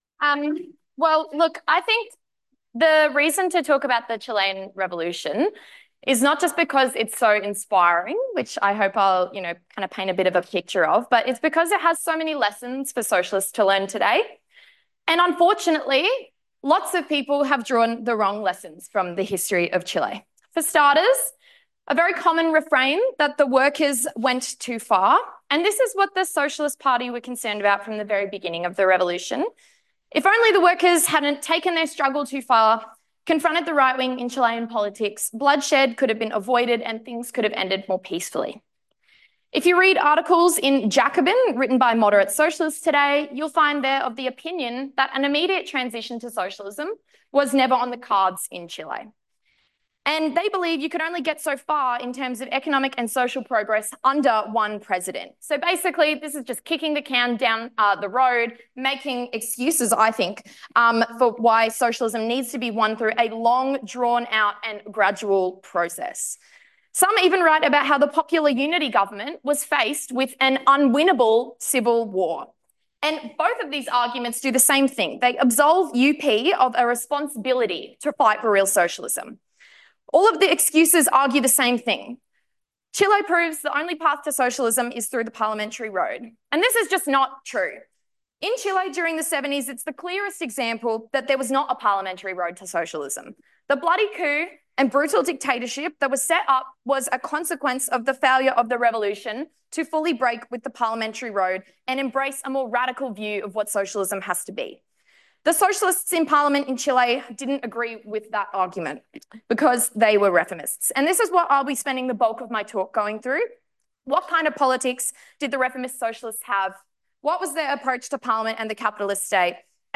Socialism 2025 (Brisbane)